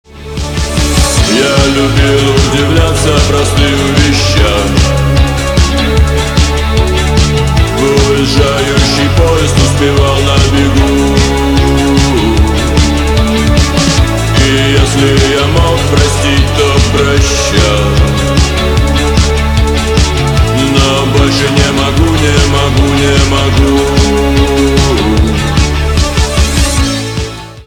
пост-панк
гитара , барабаны , чувственные , кайфовые